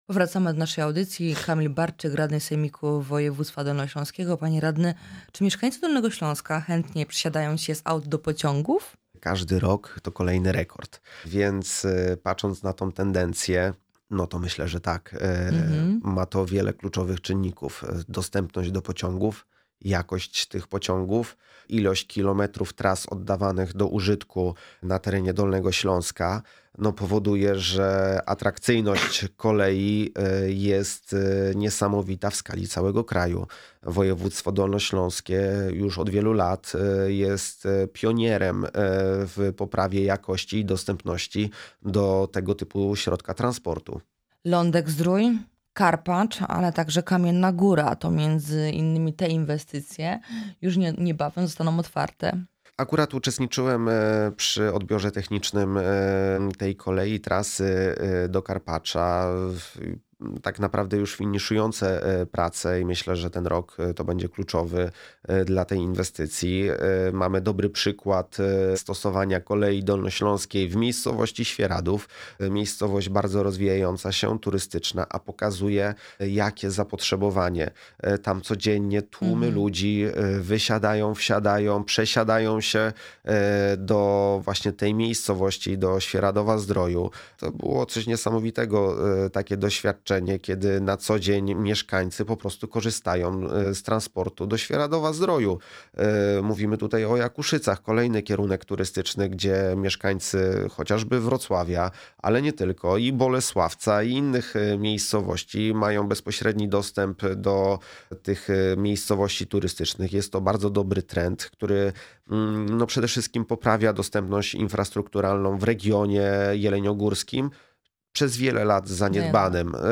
Radny Sejmiku Kamil Barczyk w audycji „Dolny Śląsk z bliska”
O usuwaniu skutków powodzi i przywracaniu infrastruktury po żywiole, który nawiedził nasz region we wrześniu ubiegłego roku, zaawansowaniu prac budowlanych szpitala onkologicznego oraz nowych połączeniach kolejowych rozmawiamy z naszym gościem. Jest nim Kamil Barczyk radny Sejmiku Województwa Dolnośląskiego (Klub Radnych: Trzecia Droga – Polskie Stronnictwo Ludowe).